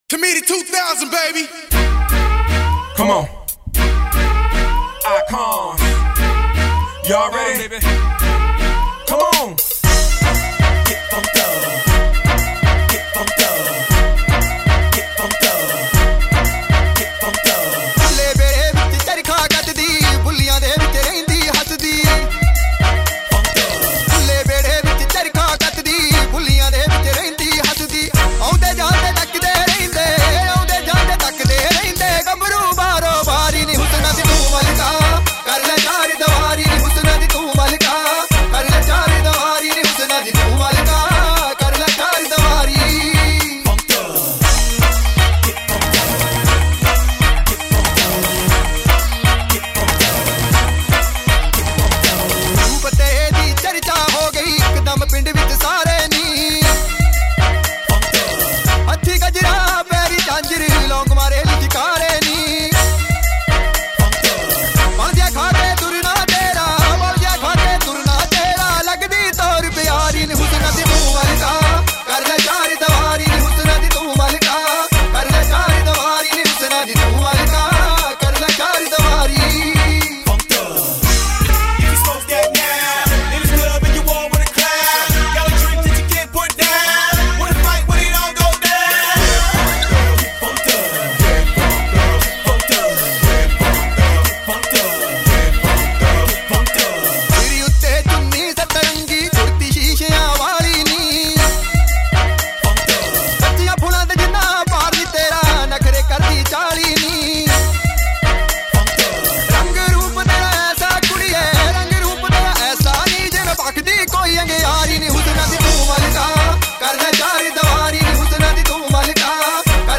Category: UK Punjabi